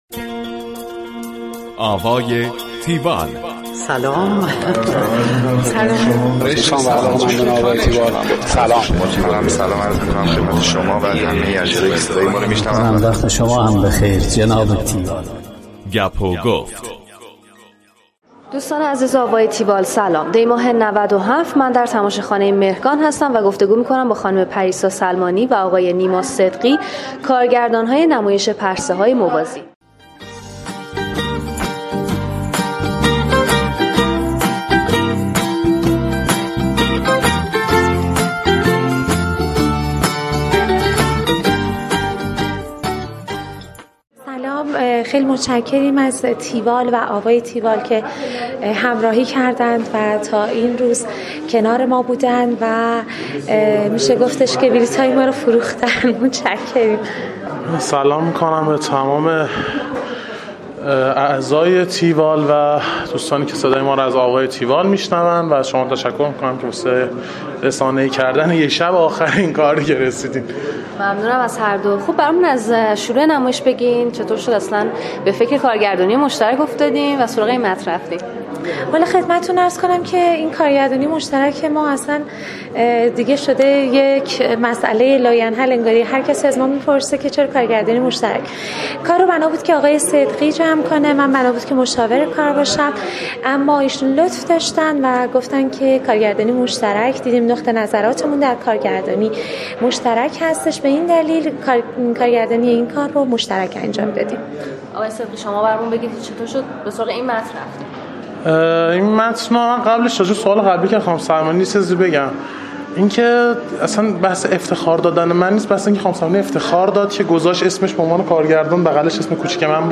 گفتگوی تیوال
tiwall-interview-parsehayemovazi.mp3